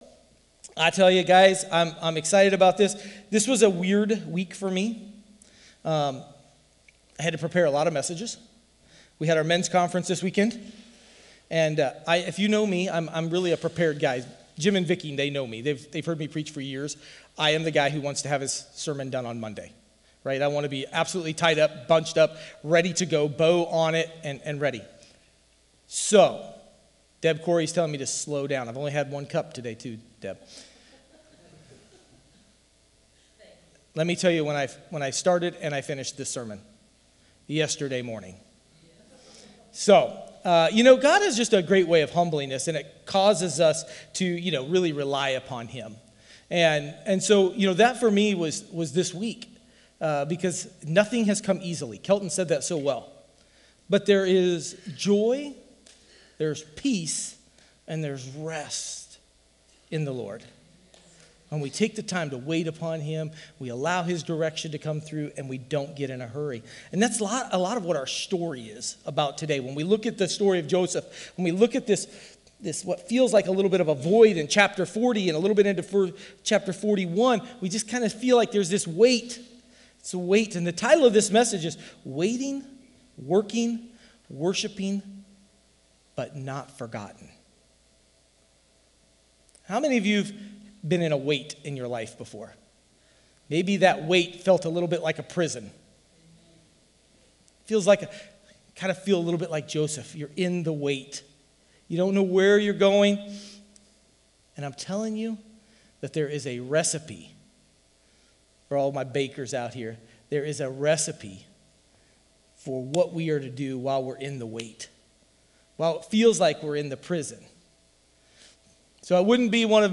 Sermons | Sunshine Open Bible Church